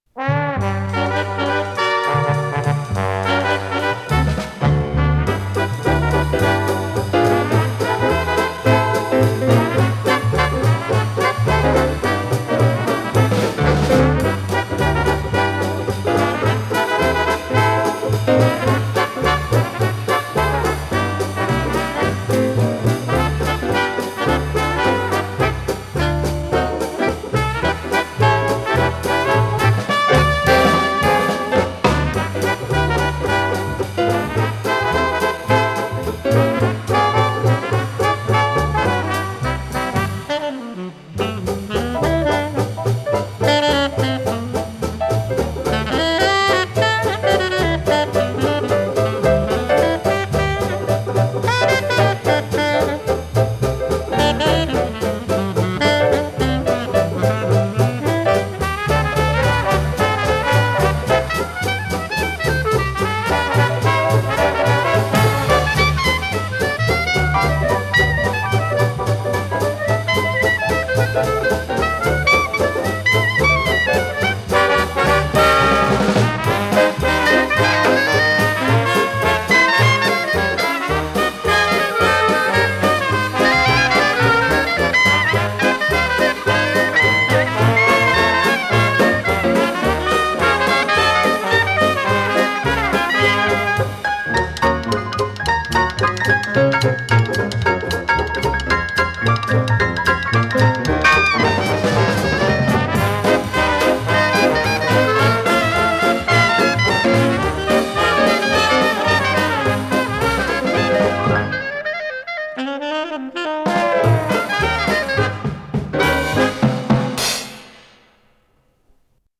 с профессиональной магнитной ленты
кларнет
тенор-саксофон
рояль